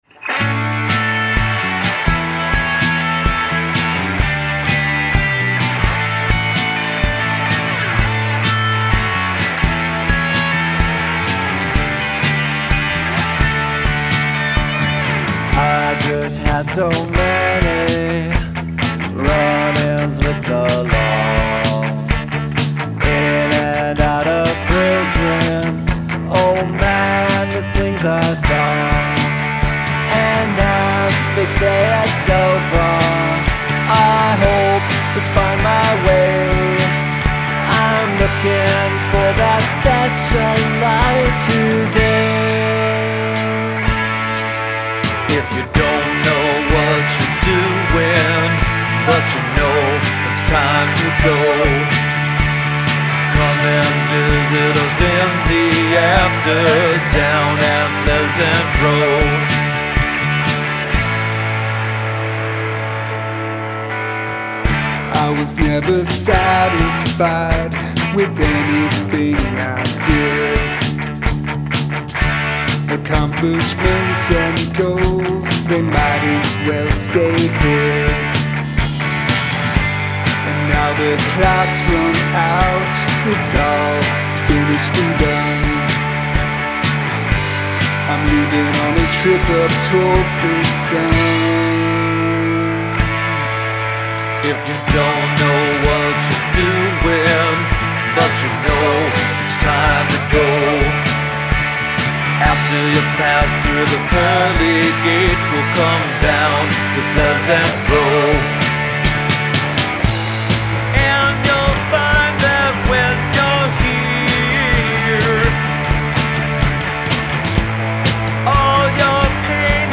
Must include a guest singing or speaking in another language
guitar, vocals
bass, vocals
drums, vocals
You bust out some decent harmonies though.